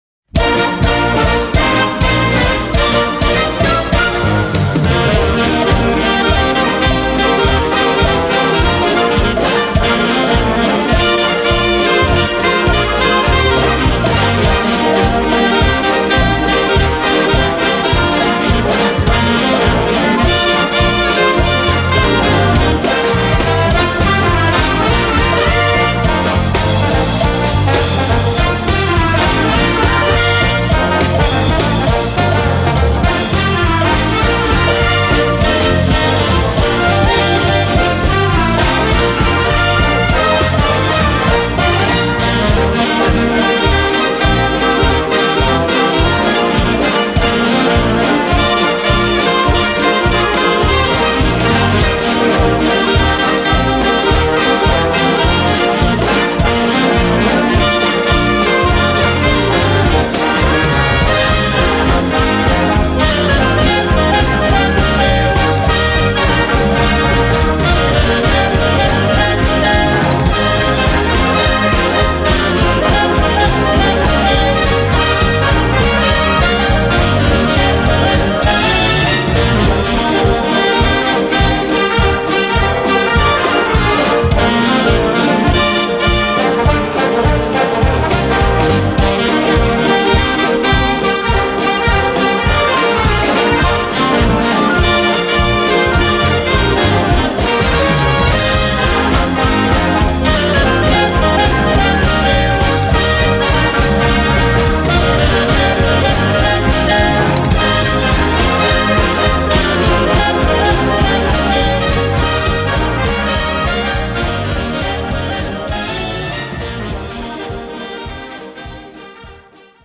Gattung: Konzertante Unterhaltungsmusik
Besetzung: Blasorchester